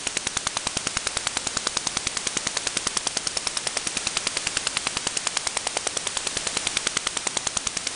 File:Chinese radar 160kHz wide USB2.mp3 - Signal Identification Wiki
Chinese_radar_160kHz_wide_USB2.mp3